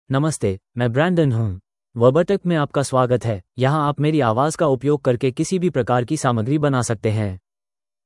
MaleHindi (India)
Brandon is a male AI voice for Hindi (India).
Voice sample
Male
Brandon delivers clear pronunciation with authentic India Hindi intonation, making your content sound professionally produced.